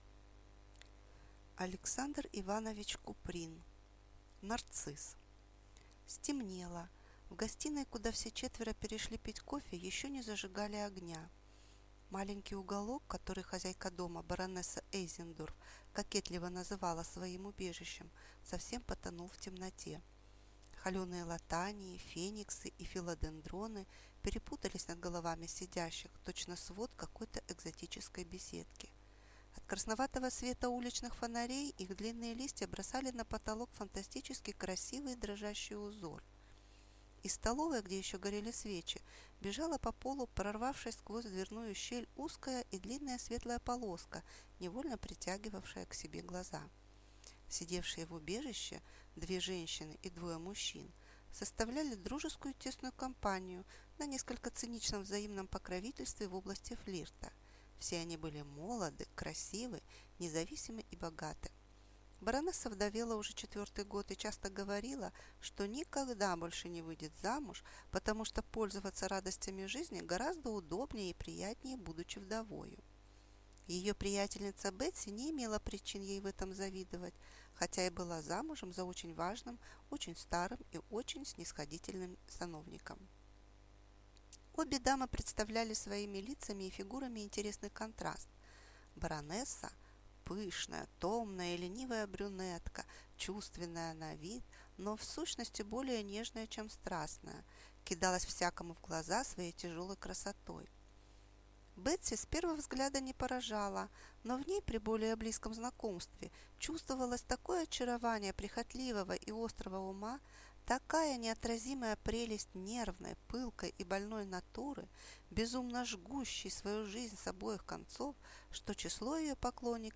Аудиокнига Нарцисс | Библиотека аудиокниг